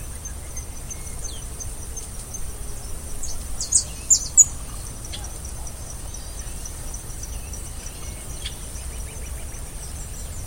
Striped Cuckoo (Tapera naevia)
Location or protected area: Reserva Natural del Pilar
Condition: Wild
Certainty: Recorded vocal